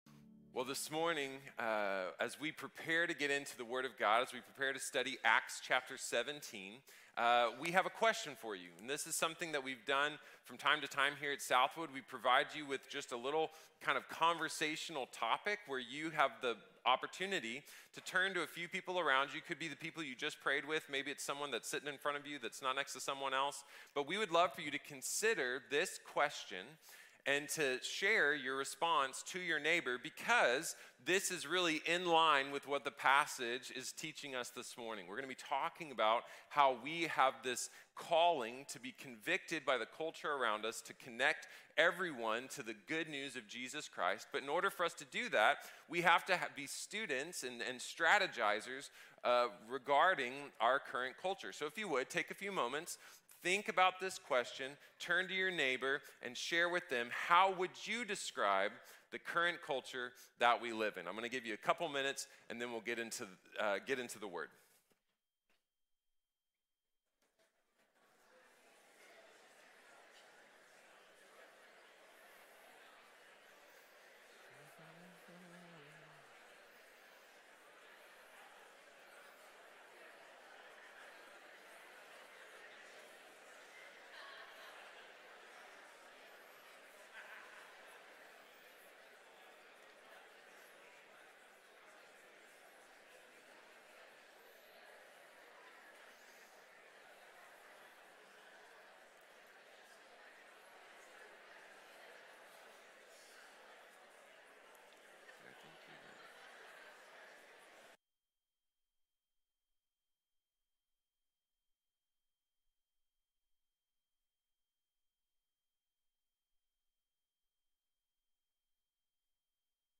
Cristo en Contexto | Sermón | Iglesia Bíblica de la Gracia